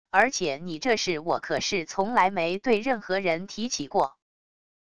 而且你这事我可是从来没对任何人提起过wav音频生成系统WAV Audio Player